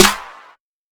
Metro Claps [Decent].wav